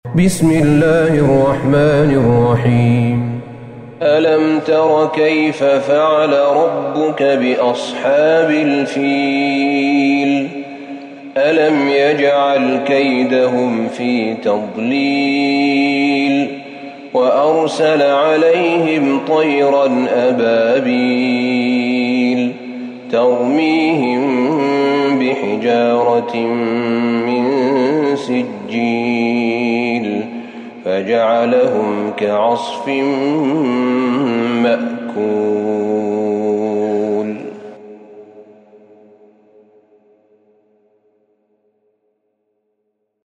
سورة الفيل Surat Al-Fil > مصحف الشيخ أحمد بن طالب بن حميد من الحرم النبوي > المصحف - تلاوات الحرمين